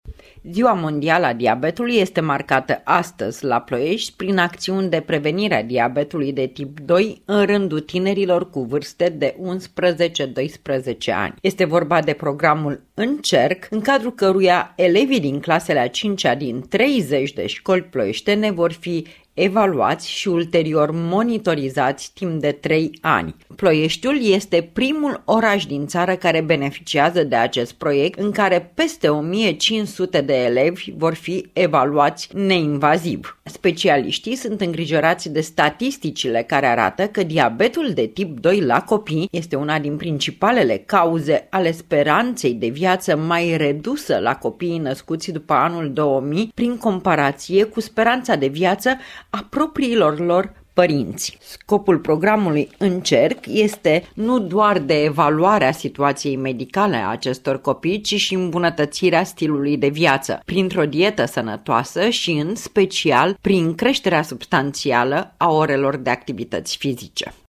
Reportaj radio difuzat la Radio România Actualități în cadrul emisiunilor „Obiectiv România”, „La dispoziția dumneavoastră” și în cadrul jurnalului orei 08:00, în data de 14 noiembrie 2017, cu ocazia Zilei Mondiale a Diabetului.